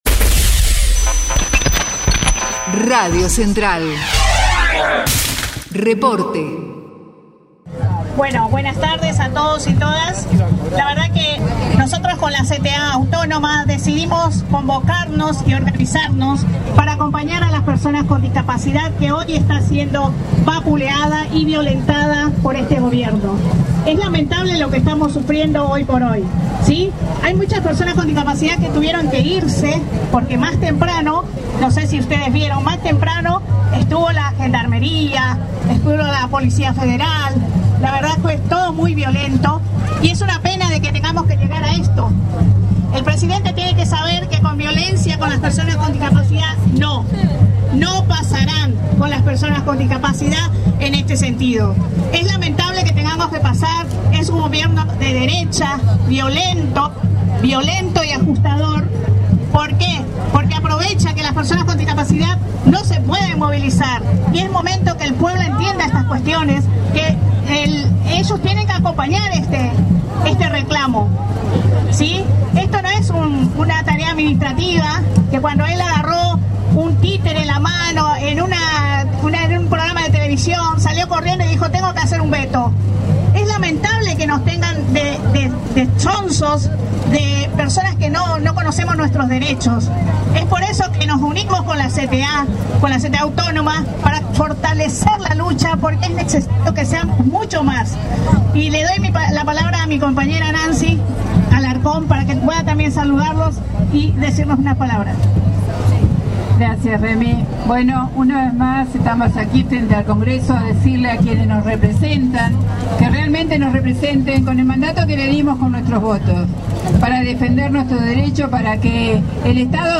CONGRESO NACIONAL: Marcha x la emergencia en discapacidad - Testimonios CTA
marcha_discapacidad_agosto.mp3